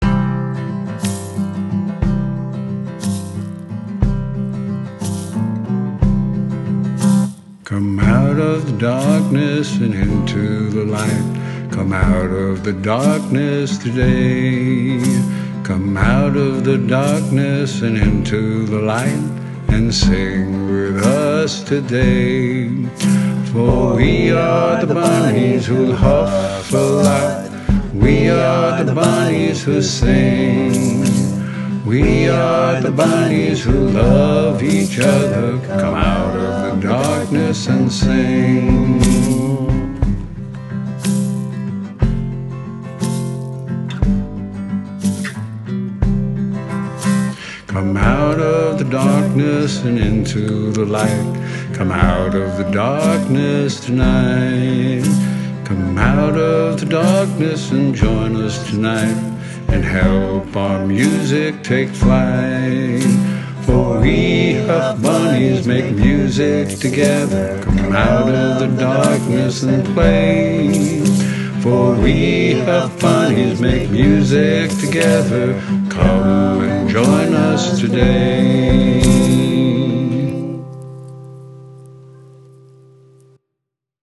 full demo (C) (2021 Transition project)